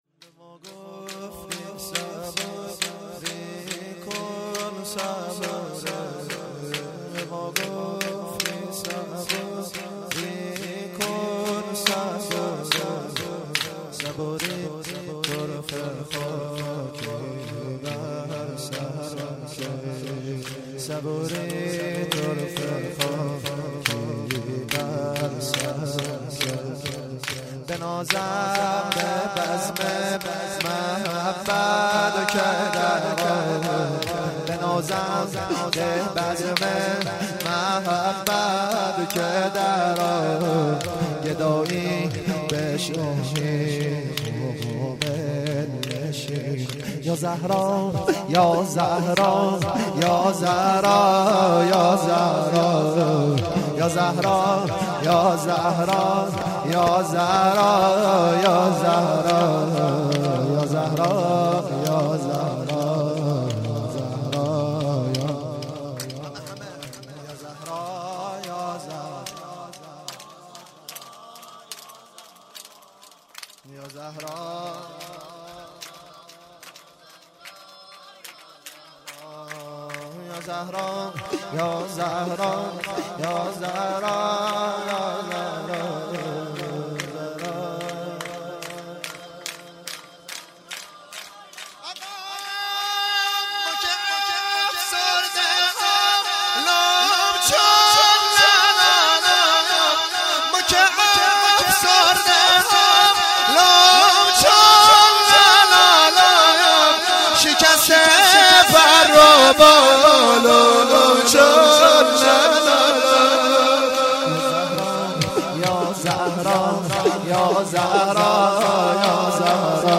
هیئت زواراباالمهدی(ع) بابلسر
0 0 شور - به ما گفتی صبوری کن صبوری
جشن ولادت حضرت زینب(س)- جمعه 29 دیماه